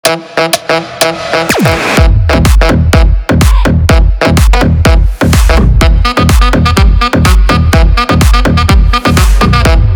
• Качество: 321, Stereo
громкие
EDM
без слов
Brazilian bass
Саксофон
house